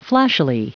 Prononciation du mot flashily en anglais (fichier audio)
Prononciation du mot : flashily